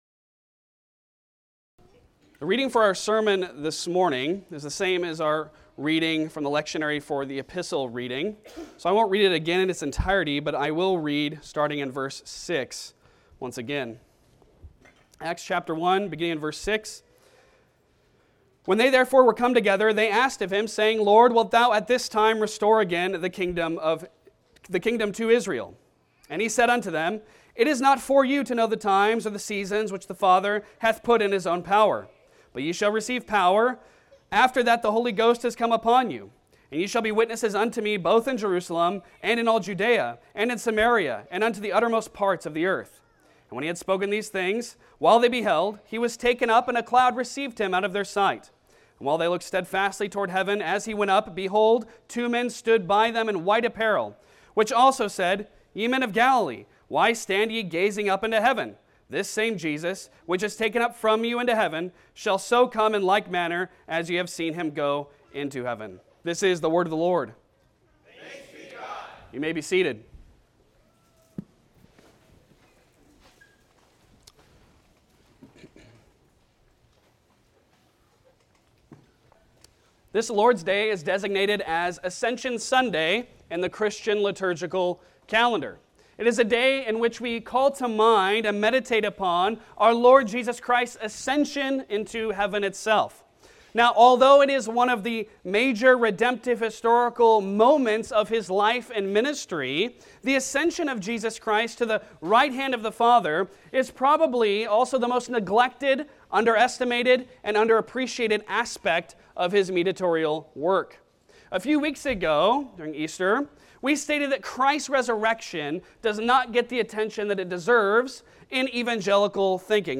Passage: Acts 1:1-11 Service Type: Sunday Sermon Download Files Bulletin Topics